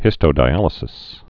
(hĭstō-dī-ălĭ-sĭs)